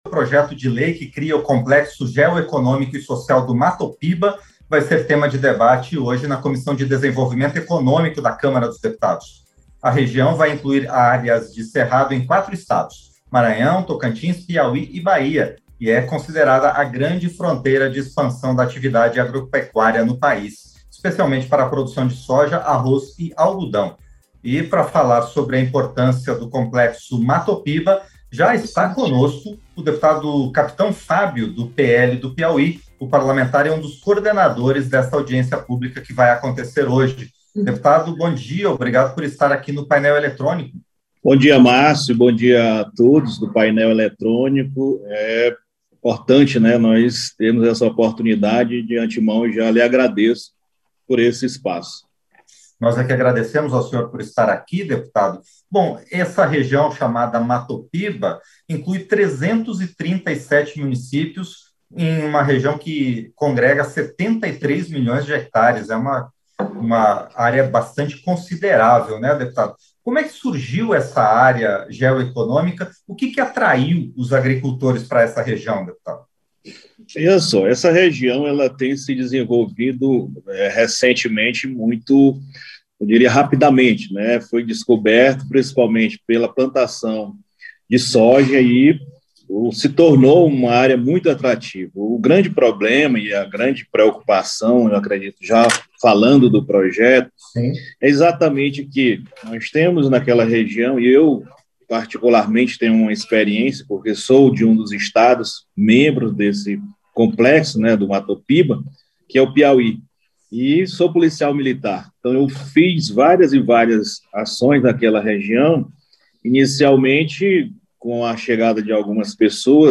Entrevista - Dep. Capitão Fábio Abreu (PL-PI)